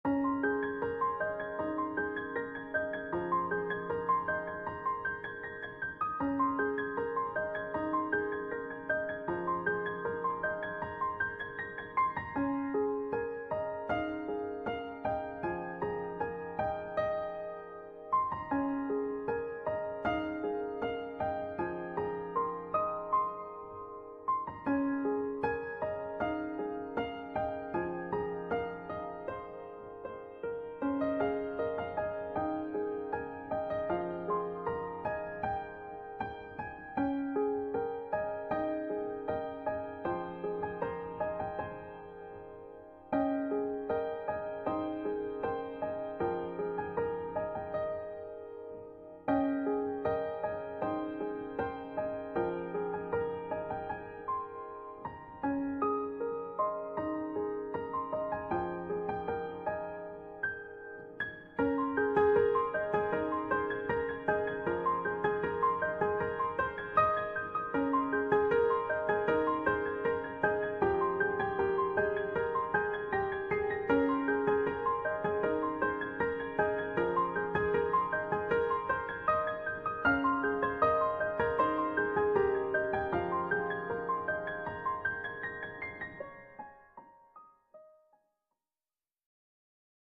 】【朗読台本】桜の雨 朗読